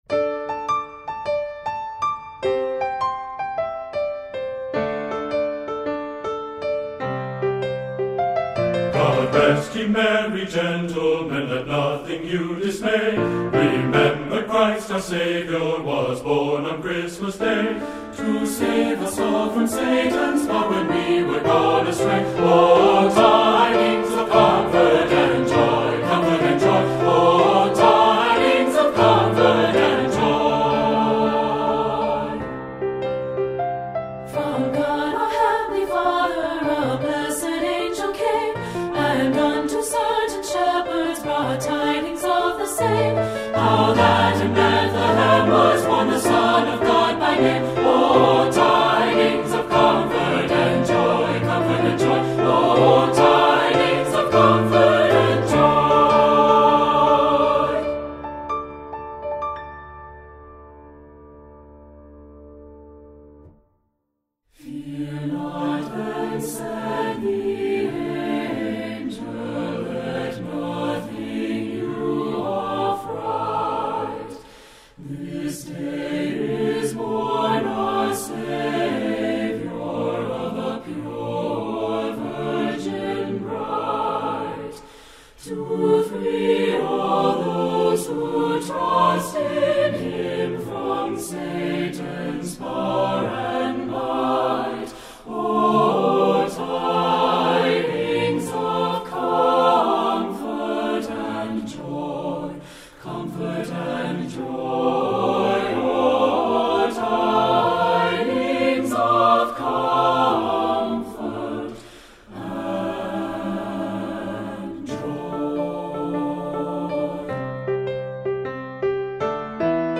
Composer: Traditional English Carol
Voicing: SAB and Piano